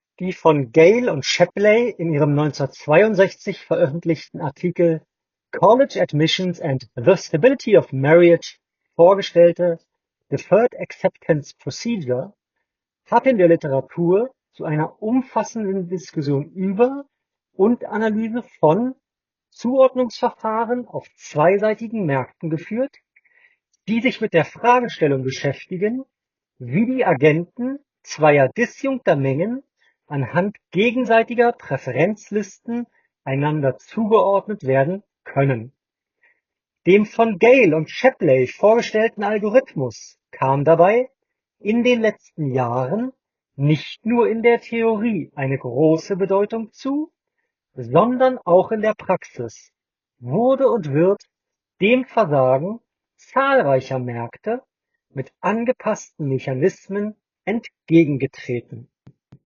Gute Sprachqualität
Bei der Telefonie filtern die FreeClip 2 enorm gut die Hintergrundgeräusche heraus, was aber auch dafür sorgt, dass der Sprecher nicht mehr ganz präsent klingt, sondern als wäre er etwas zu weit von den Mikrofonen weg. Klangcharakteristik der Stimme und Verständlichkeit sind gut und weniger nasal als beim Vorgänger.
Huawei FreeClip 2 – Mikrofonqualität